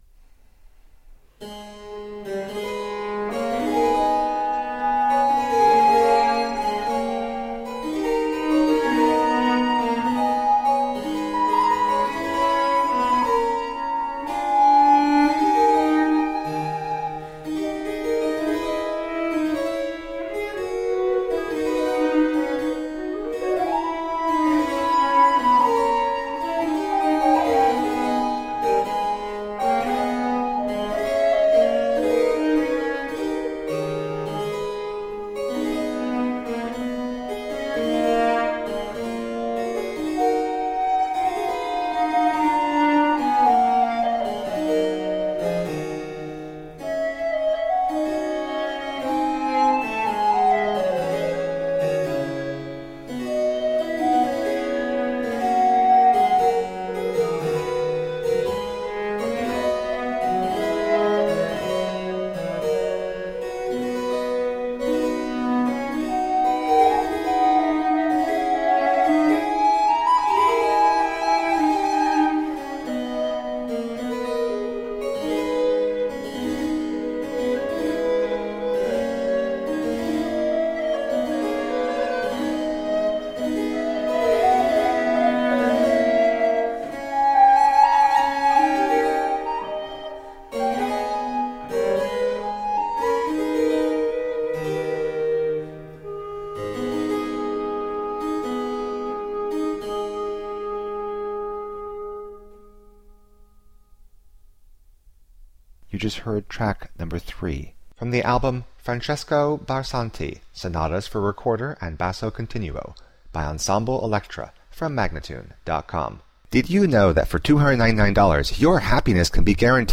Classical, Baroque, Instrumental
Cello, Harpsichord, Recorder